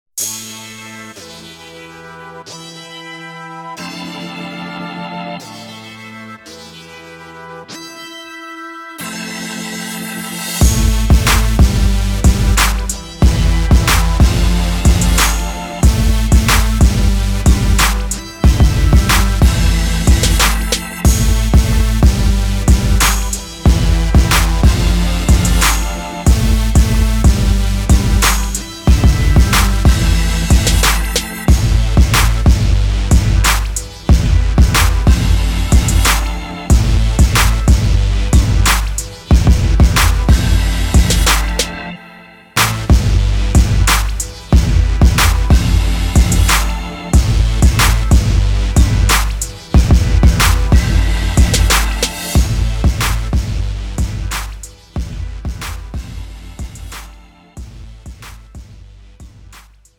장르 pop 구분 Premium MR
Premium MR은 프로 무대, 웨딩, 이벤트에 최적화된 고급 반주입니다.